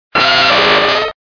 Fichier:Cri 0112 DP.ogg
contributions)Televersement cris 4G.